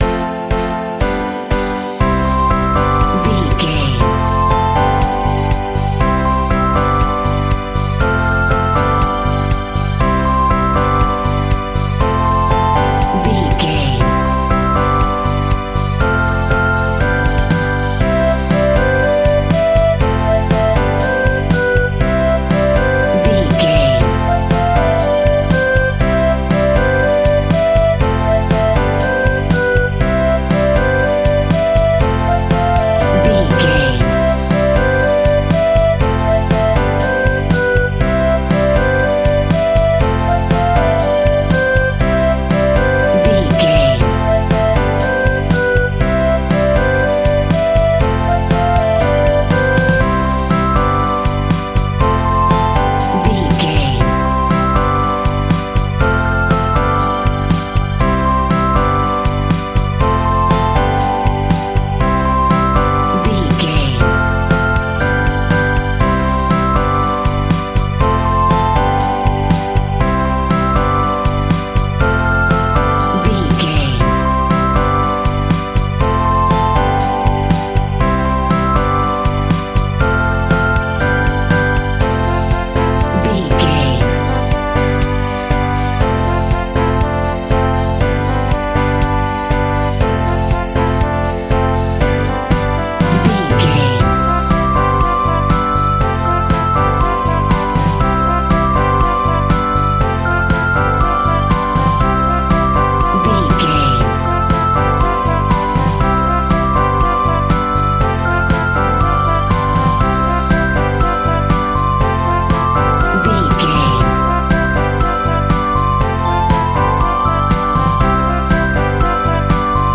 royalty free music
Uplifting
Ionian/Major
childrens music
childlike
cute
happy
kids piano